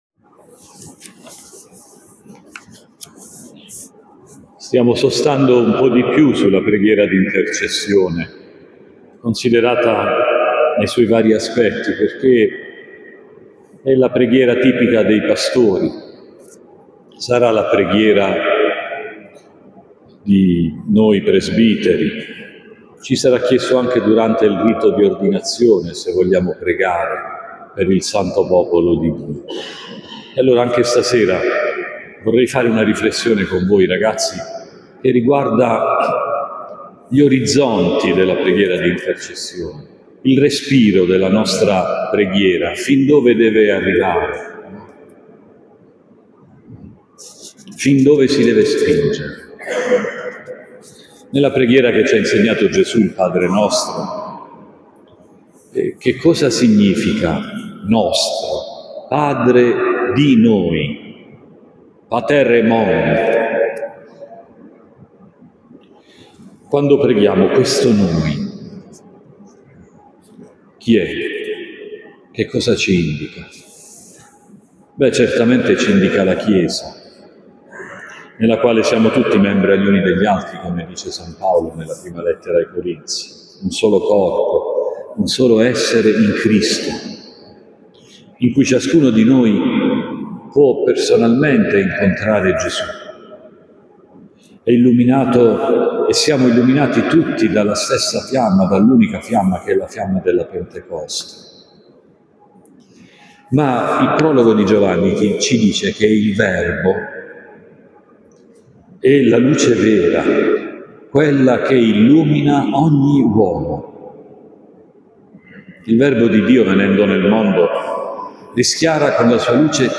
In questa pagina puoi trovare i podcast delle meditazione del Rettore durante i Vespri comunitari sulla Traccia formativa
Omelia Vespri VIII del Tempo Ordinario